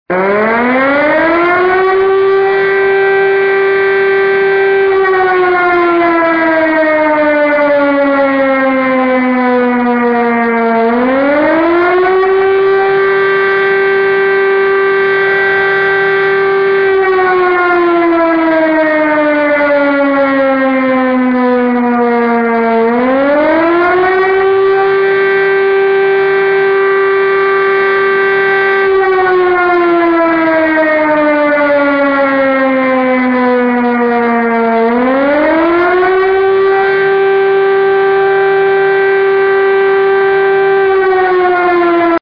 longalarm.mp3